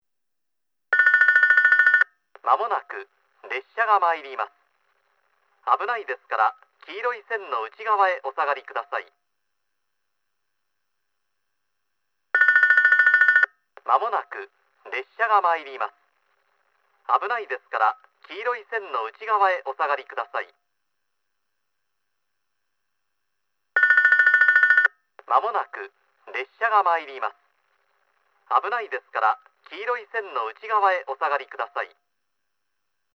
接近放送　男声（1，2番のりば共通）